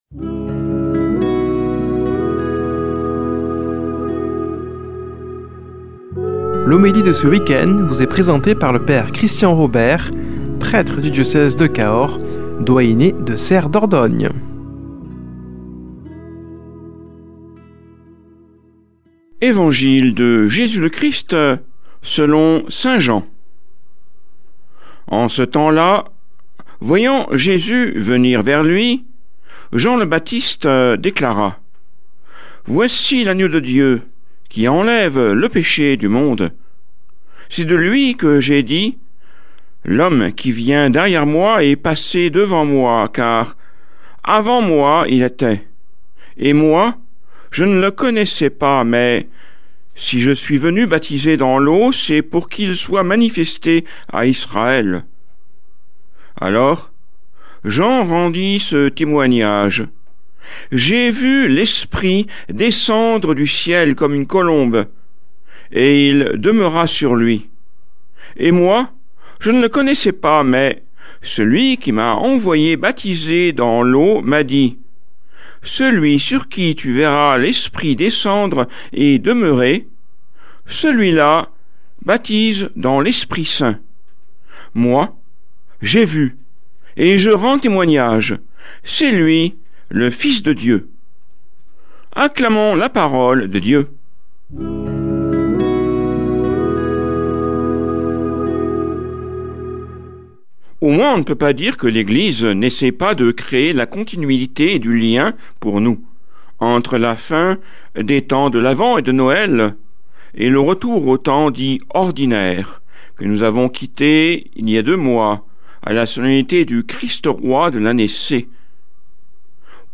Homélie du 17 janv.
Présentateur